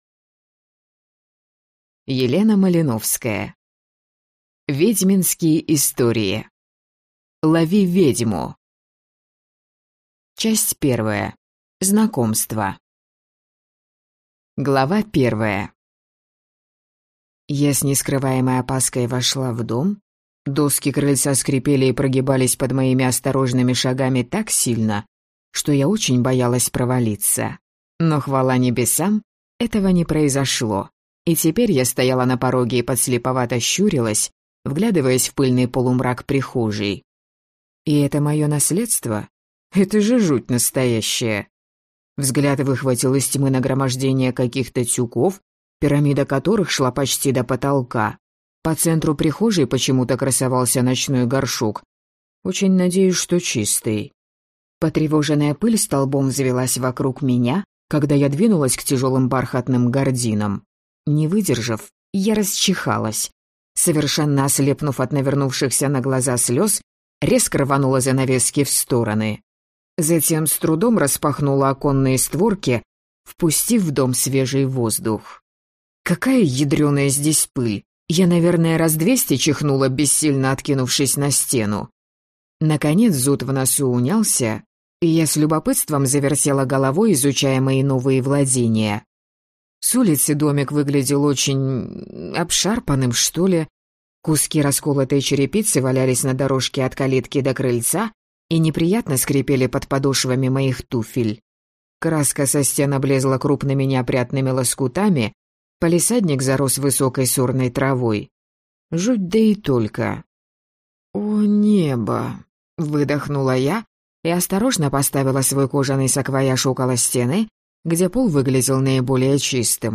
Аудиокнига Ведьминские истории. Лови ведьму!